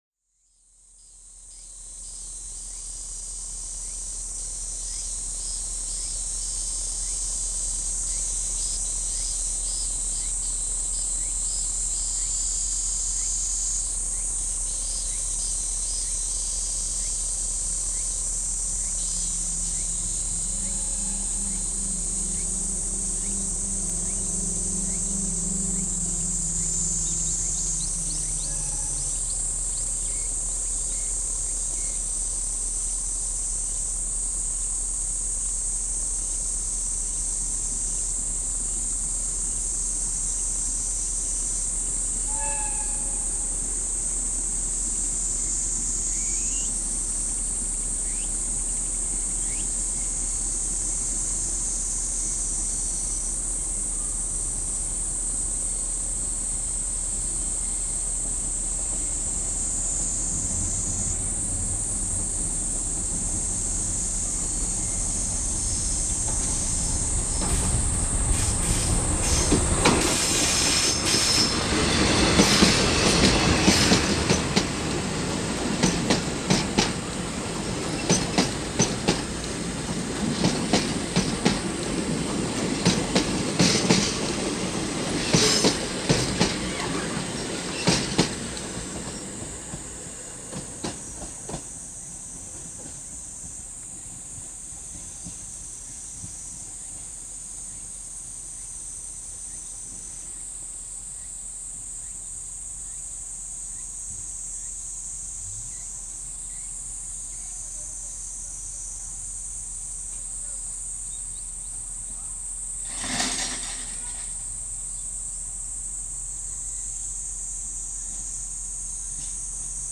国鉄福知山線武田尾駅でのＤＤ５４とＤＤ５１の交換の音風景
国鉄福知山線武田尾駅の貨物ホーム
セミ時雨の中、既にＤＤ５４牽引の上り客レが左手のホームに停車している。 遠方で下り客レのＤＤ５１のエンジン音と汽笛が聞こえ、どんどん近づいて来る。 減速しながら目の前を通過してホームに入ると、下り進入の腕木信号が上がり、次に上り出発信号が下がる。 焼きつくような長い時間が流れたあと、漸く出発のブザーが鳴り、ＤＤ５４が汽笛を鳴らして発車する。 西独マイバッハ社製の１８２０馬力のエンジン音を轟かせながら加速し（変速の為か途中でエンジン回転が一旦落ちるのが判る）、Ｂ−１−Ｂの車輪音を響かせて目の前を通過する。 加速するに従って旧型客車の長い軸間の車輪の音もその間隔を早め、やがて最後尾も通り過ぎてしまう。 ＤＤ５４は武庫川の渓谷に汽笛を響き渡らせながら遠ざかって行く。 今の列車から降りた人々を乗せているであろう小型バスのクラクションとエンジン音が聞こえた後は、セミの声に包まれた山間の静かな駅に戻った･･･。
左端に写る貨物ホームで録音（写真は昭和６１年）
ラジカセによる録音の為、録音レベルはオートマチック調整されていますが、比較的良好な音質で、ステレオならではの臨場感もあります。
ＳＯＮＹステレオラジオカセットＣＦ−６５００「ジルバップ」（マイク内蔵）でナショナル・テクニクスのノーマルテープに録音したものを、ＤＡＴを介してＭＰ３に変換。